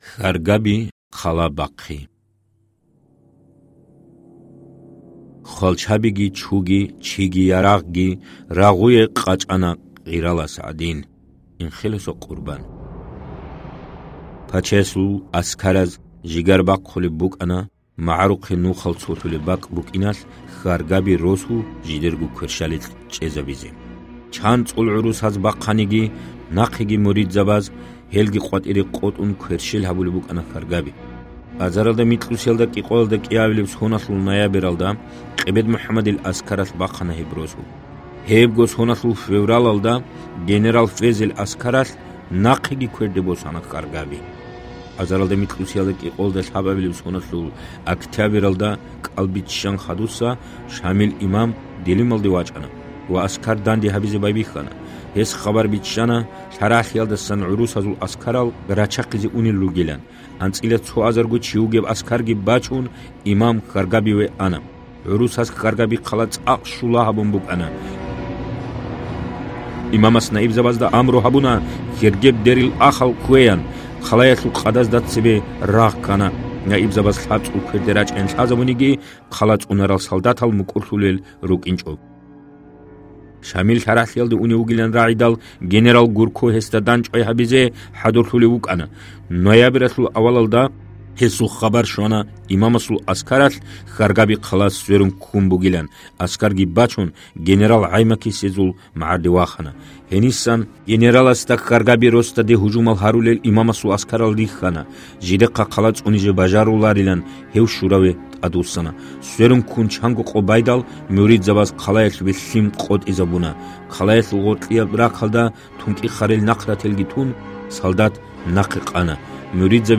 МухIамад ХIамзаев. "Имам Шамил". ТIехьалъул аудио версия. 50-59 бутIаби.